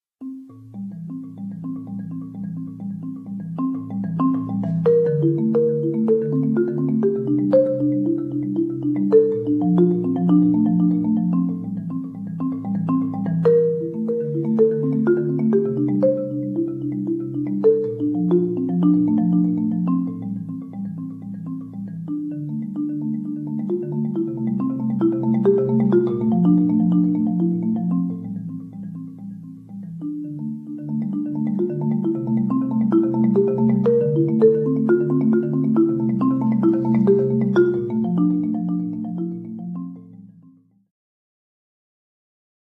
skrzypce / fiddle
marimba, moog
perkusja / drums, moog